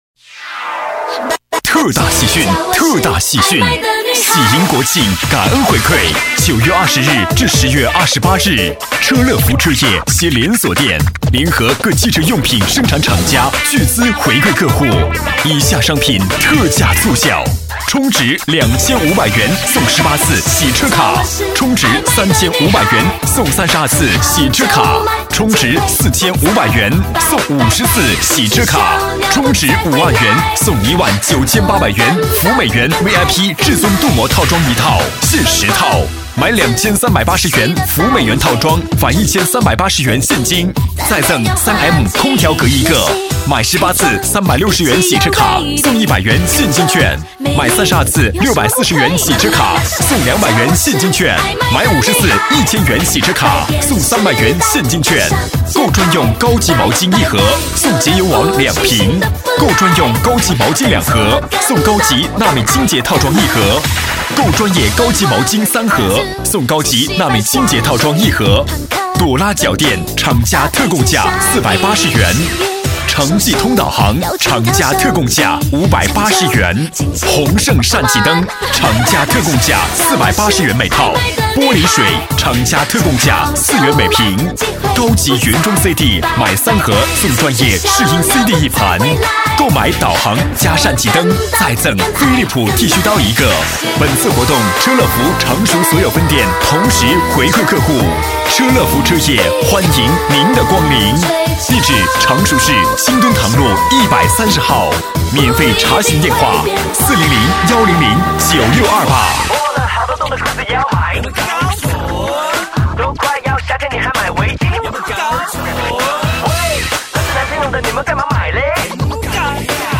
促销配音是指配音员对产品促销广告词进行配音的过程，促销广告配音跟一般的电视广告配音不同，促销广告配音在风格上，一般男声配音都比较激情，女声配音欢快、时尚。
男声配音